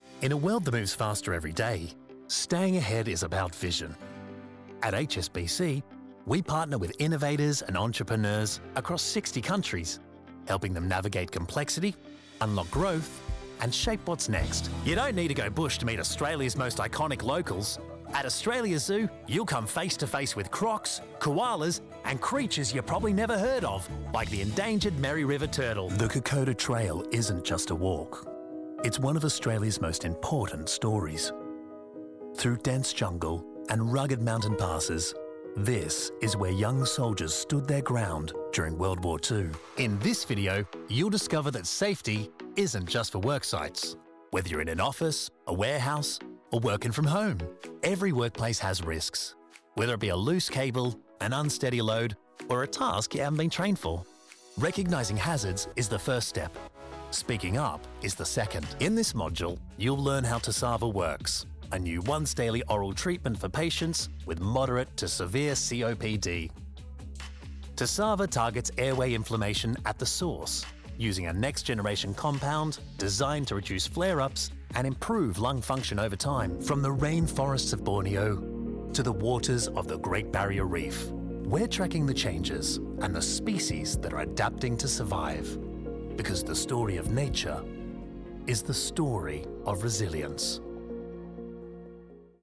A warm, authoratative, yet versatile voice available for all your corporate and commercial readings.